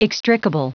Prononciation du mot extricable en anglais (fichier audio)
Prononciation du mot : extricable